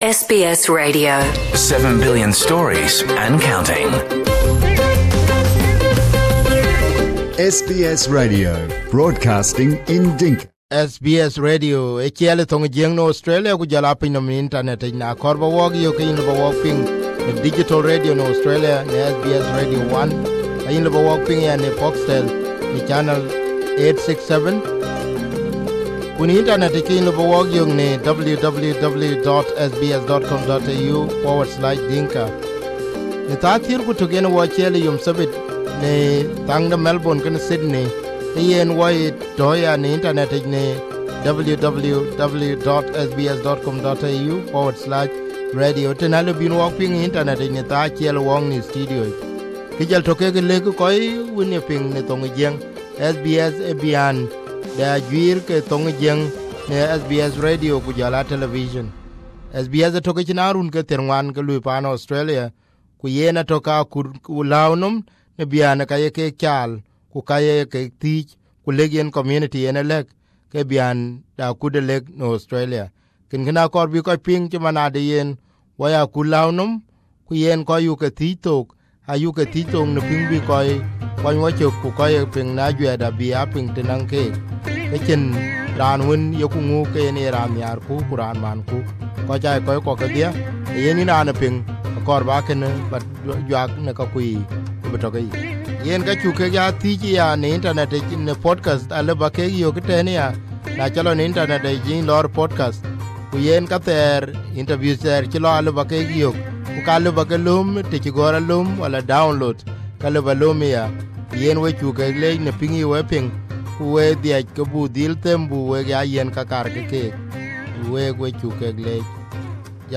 Did SPLM as a party failed the people of South Sudan? This exclusive interview on SBS Dinka is with Nhial William Deng Nhial. Nhial Deng Nhial is a senior member of the SPLM Political Bureau and the South Sudan Governments Chief negotiator on peace with the SPLM in Opposition in Addis Ababa.